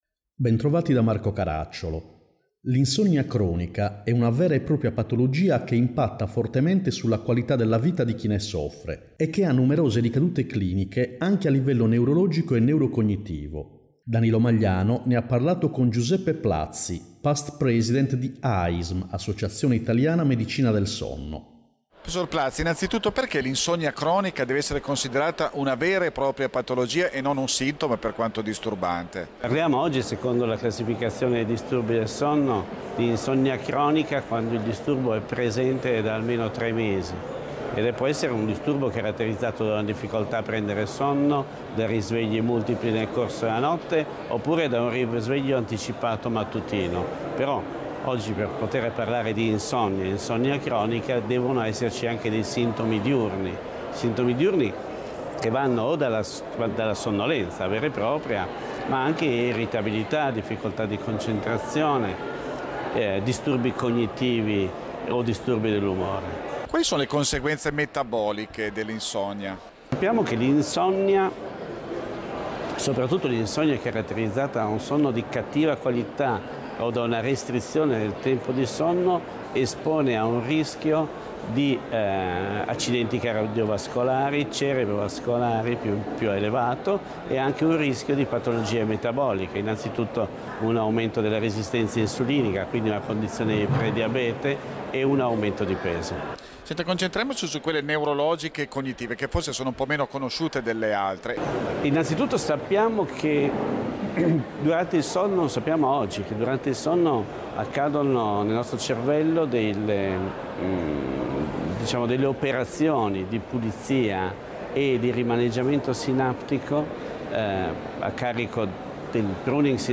Salute/Società: rubrica di divulgazione medico-scientifica prodotta da Emmecom
Puntata con sigla